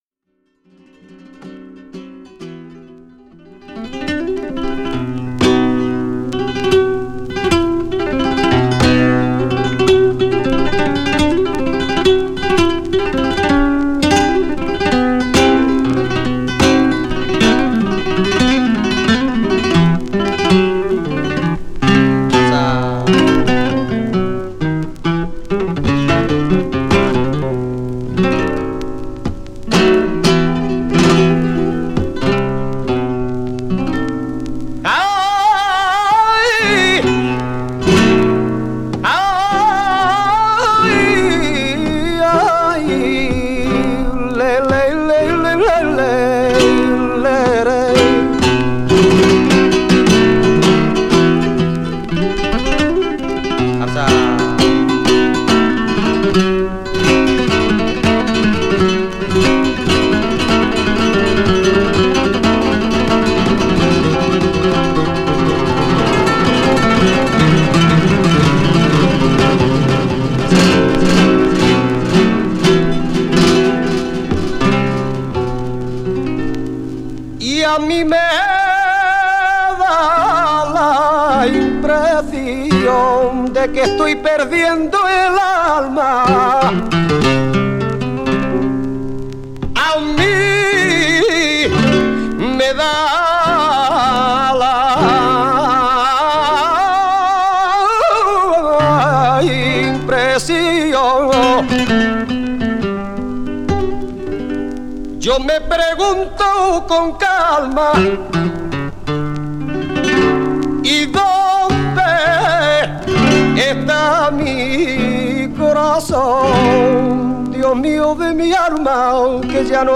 Ambient Avant-Garde